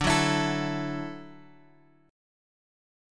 Dadd9 chord {x 5 4 x 5 5} chord
D-Major Add 9th-D-x,5,4,x,5,5.m4a